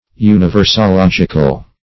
Universological \U`ni*ver`so*log"ic*al\, a.